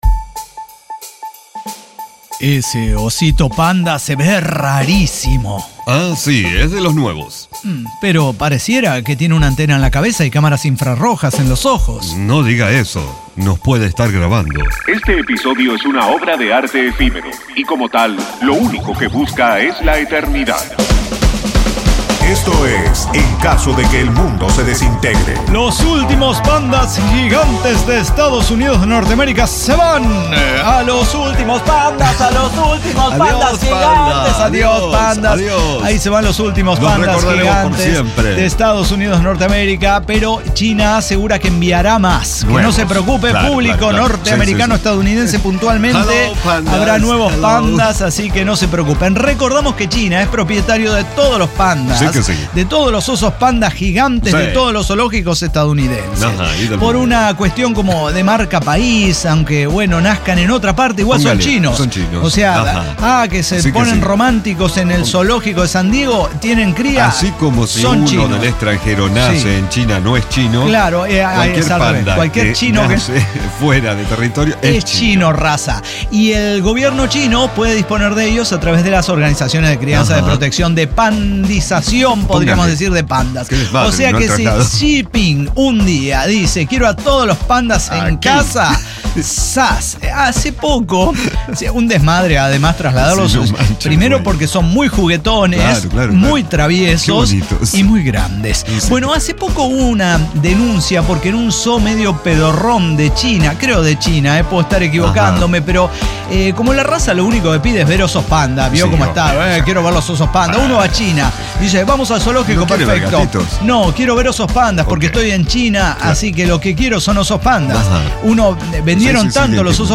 ECDQEMSD podcast El Cyber Talk Show – episodio 5789 Peruanos En El Espacio